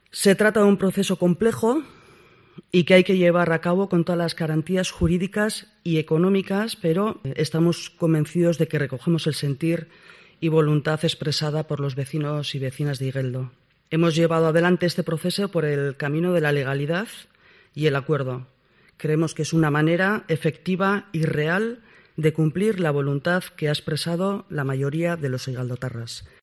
Maria Jesús Idoeta, concejal de Barrios de San Sebastián